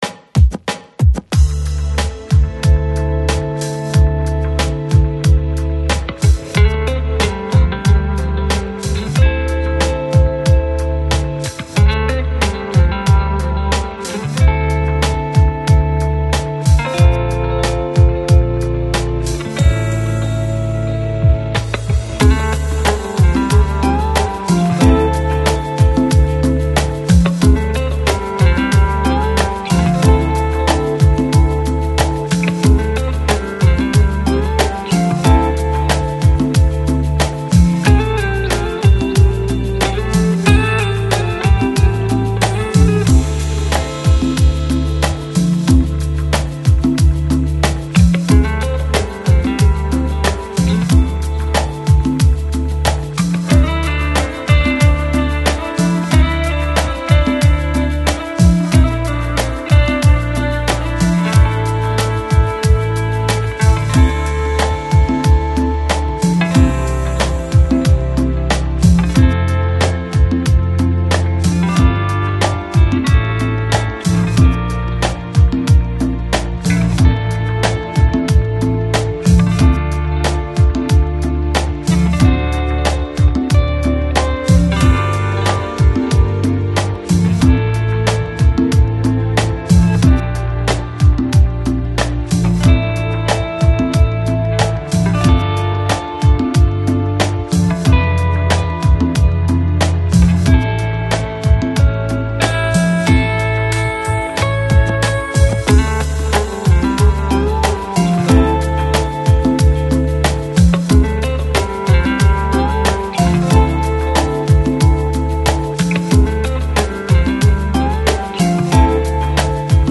Electronic, Chill Out, Lounge, Downtempo